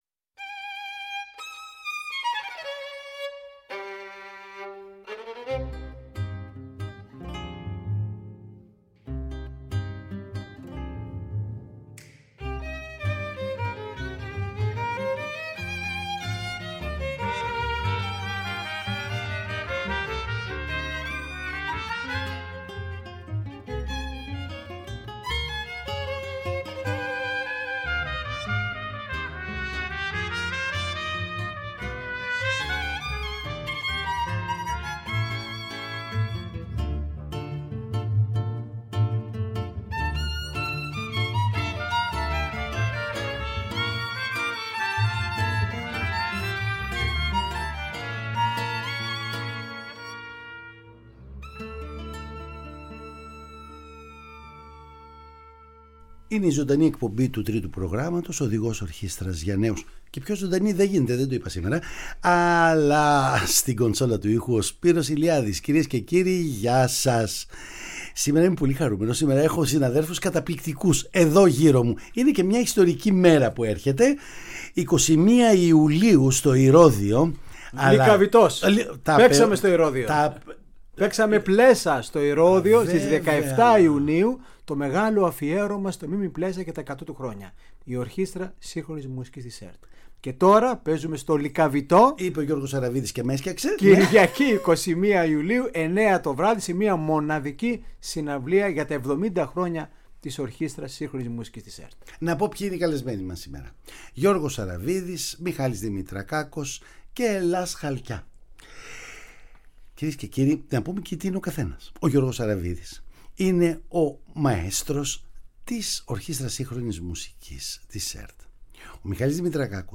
Λίγες μέρες πριν από τη συναυλία της Ορχήστρας Σύγχρονης Μουσικής της ΕΡΤ στο θέατρο Λυκαβηττού στις 21 Ιουλίου, μια συναυλία – αναδρομή στην εβδομηντάχρονη πορεία της ιστορικής Ορχήστρας, ένας φόρος τιμής στους δημιουργούς της, στους σπουδαίους μουσικούς που την υπηρέτησαν, καθώς και στους συνθέτες, ενορχηστρωτές, τραγουδιστές, ηθοποιούς και σολίστ που την τίμησαν με τη συνεργασία τους, υποδεχόμαστε στο στούντιο του Τρίτου τους ανθρώπους της.